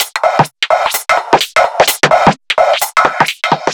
Index of /musicradar/uk-garage-samples/128bpm Lines n Loops/Beats